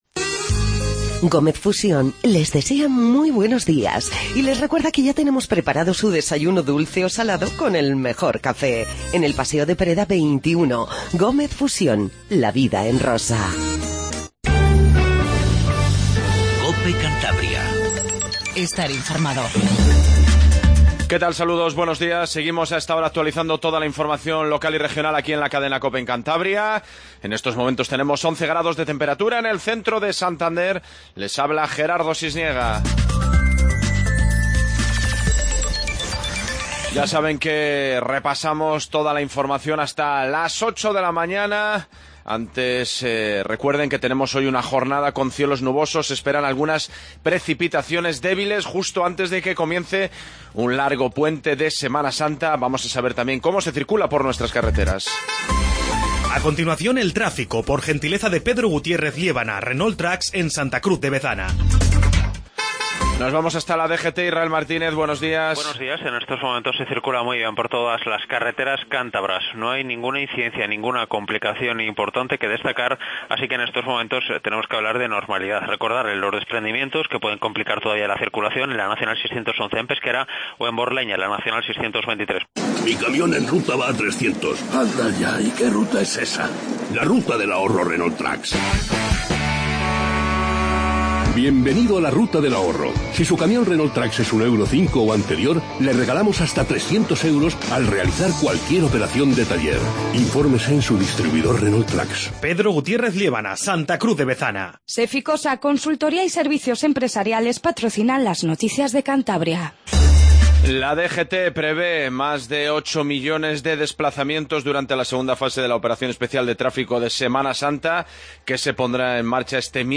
INFORMATIVO MATINAL 07:50